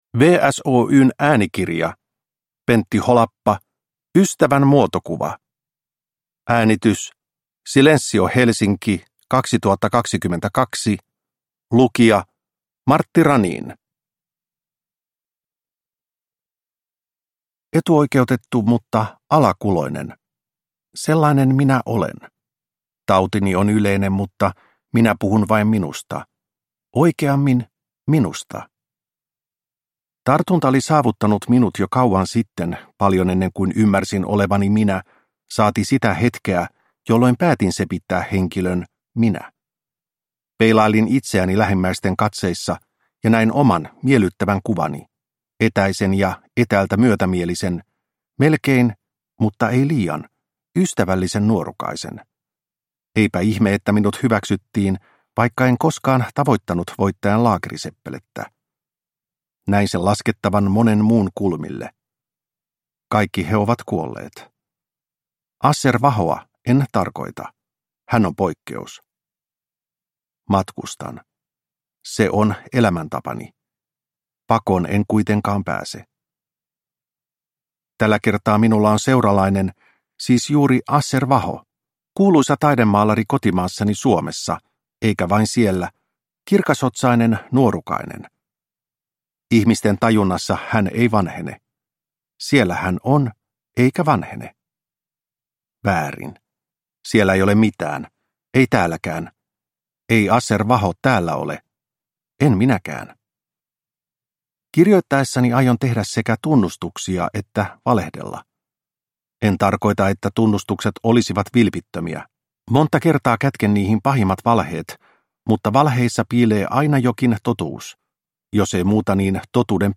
Ystävän muotokuva – Ljudbok